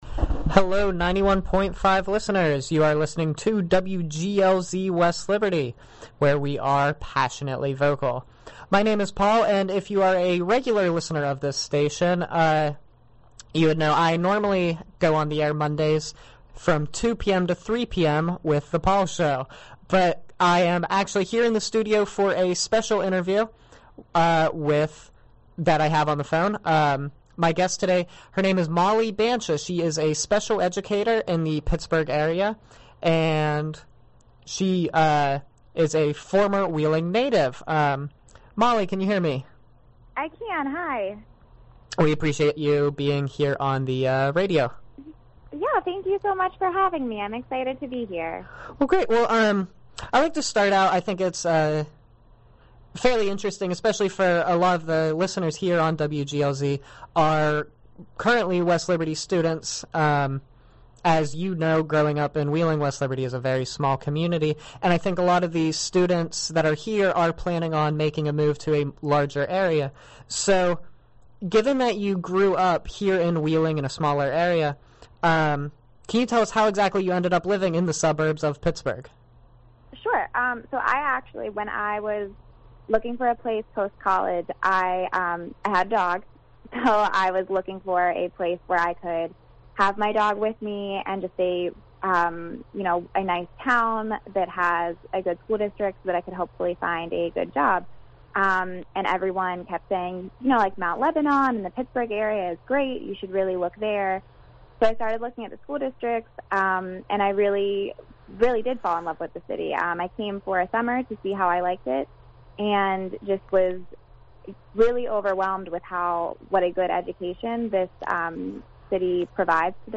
Being that it is a college radio station, WGLZ has a passionate interest in education.